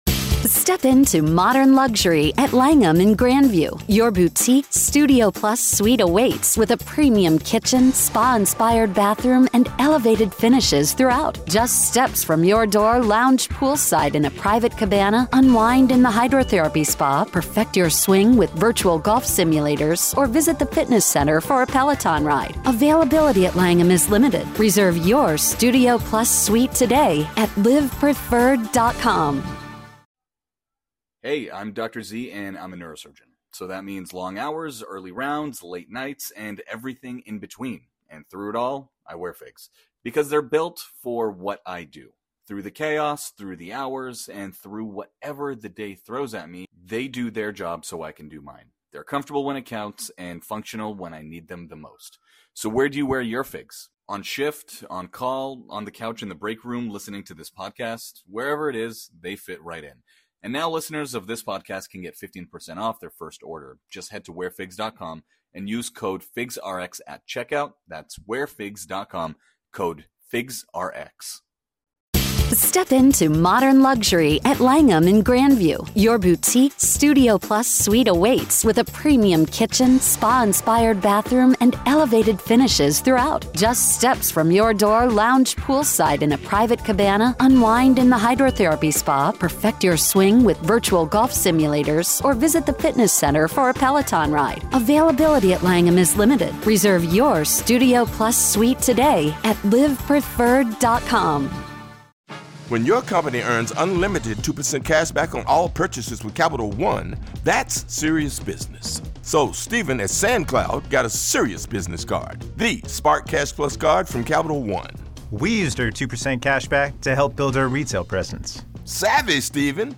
In this gripping interview